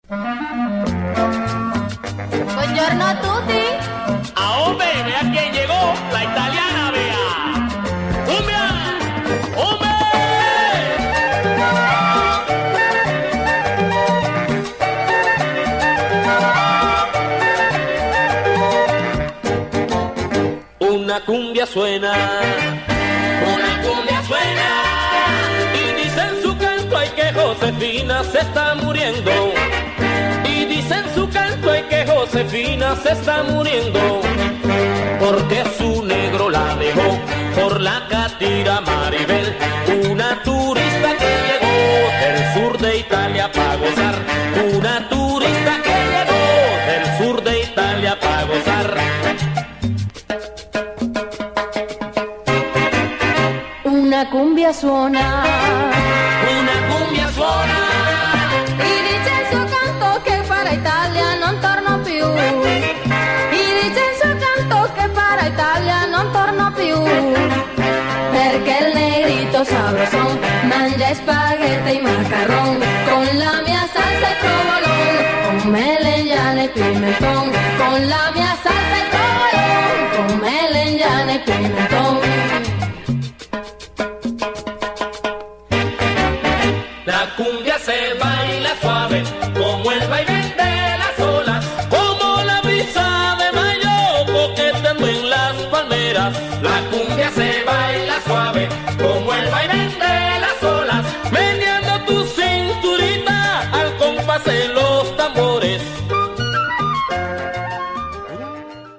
bitter-suave cumbia tune about Josefina